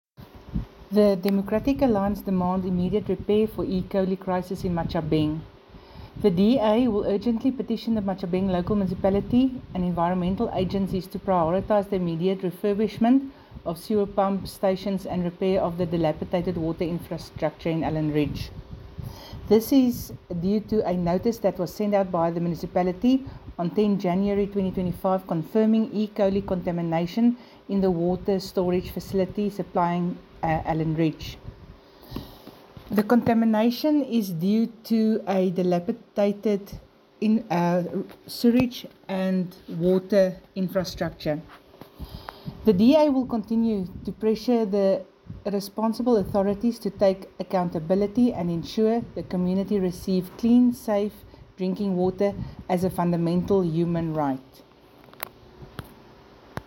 Afrikaans soundbites by Cllr Jessica Nel and